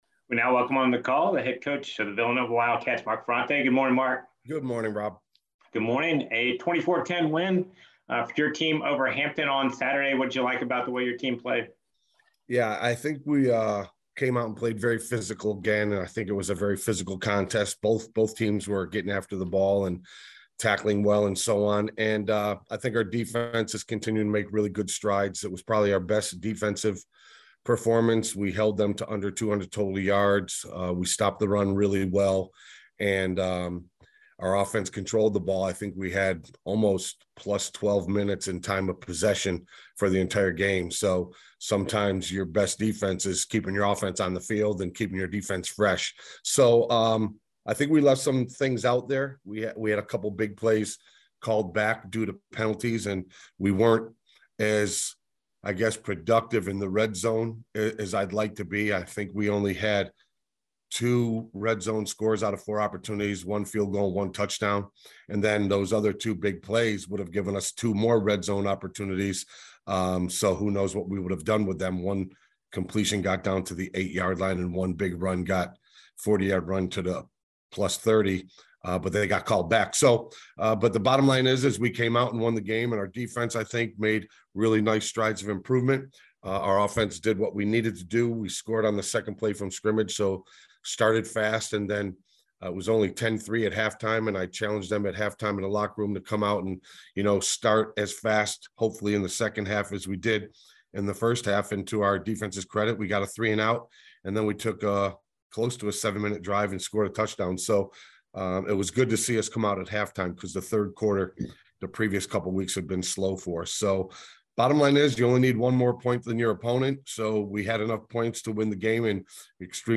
CAA Football Coaches Weekly Press Conference - October 31
All 13 CAA Football head coaches took questions from the media on Monday in advance of Week 10 of the season.